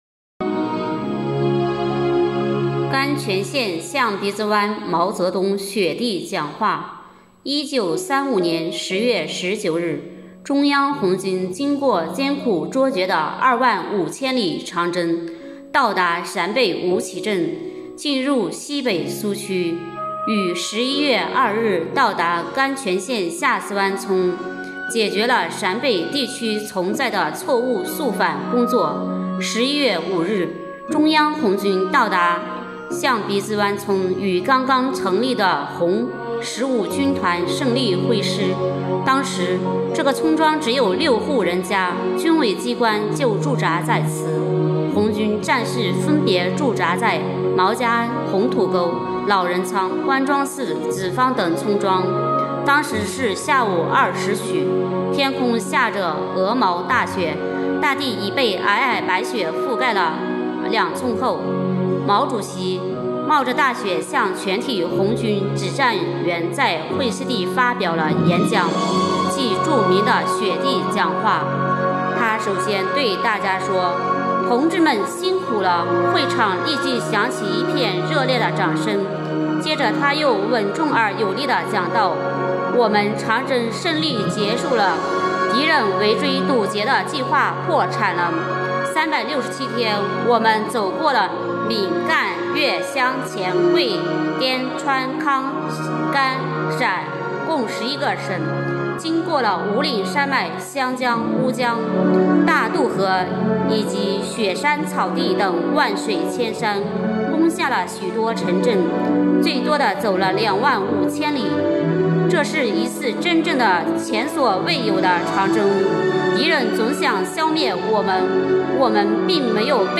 【红色档案诵读展播】毛泽东在雪地里的讲话